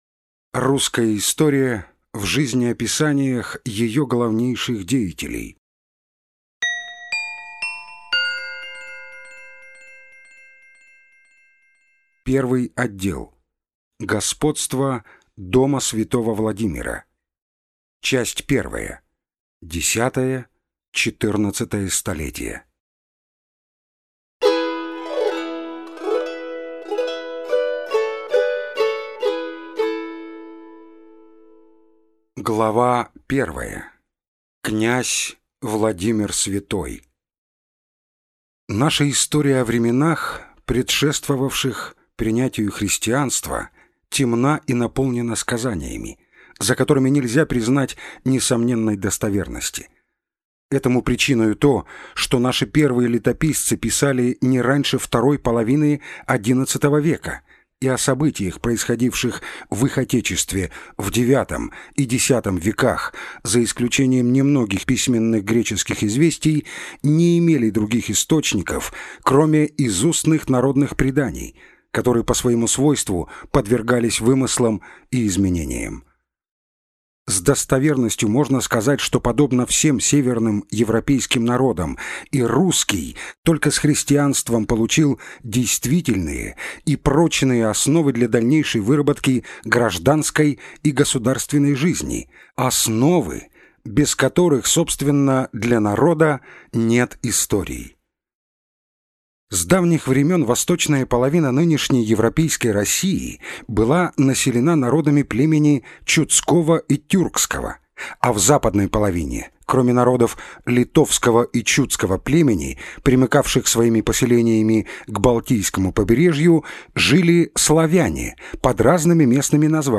Аудиокнига Русская история в жизнеописаниях. Выпуск 1 | Библиотека аудиокниг